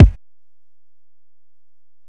Kick (38).wav